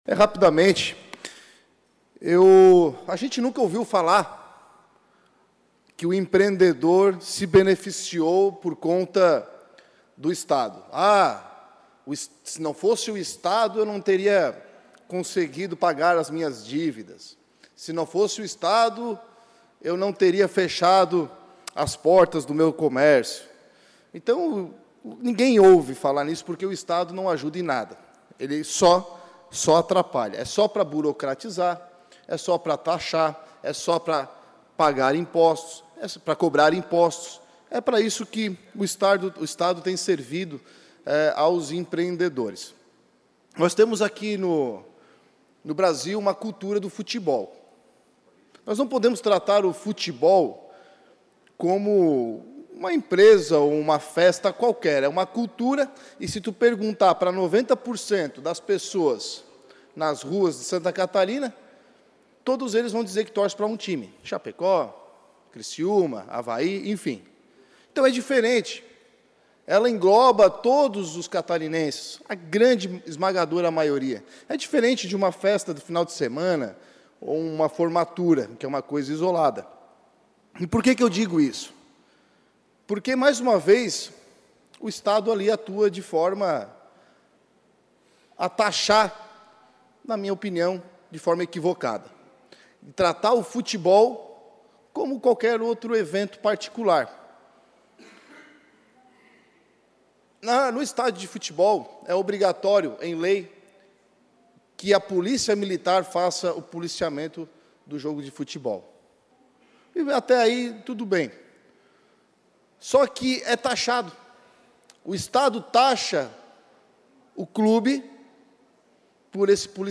Pronunciamentos da sessão ordinária desta terça-feira (21)
Confira os pronunciamentos dos deputados na sessão ordinária desta terça-feira (21):